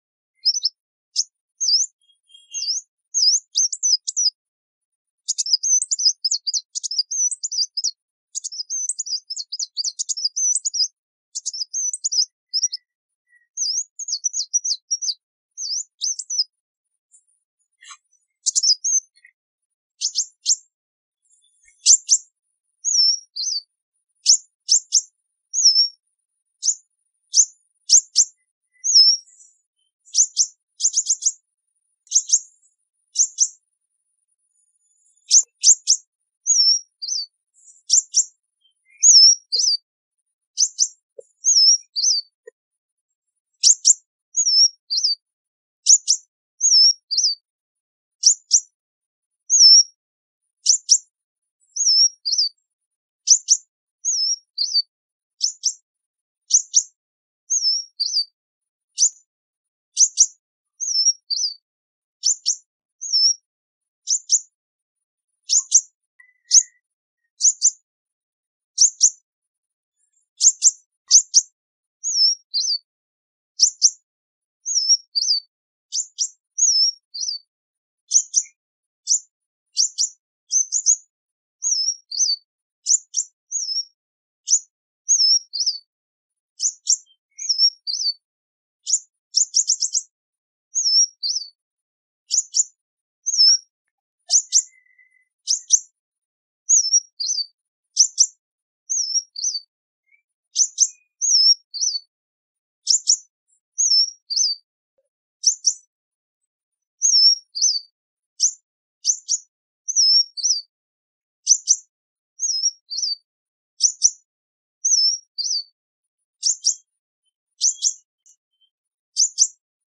Tiếng Hút Mật
Tiếng hút mật 5 màu hót Tiếng Hút Mật 5 màu líu
Thể loại: Tiếng chim
Âm thanh này được ghi âm với chất lượng chuẩn nhất, rõ ràng và to, giúp tăng cường trải nghiệm nghe cho người xem.
tieng-chim-hut-mat-www_tiengdong_com.mp3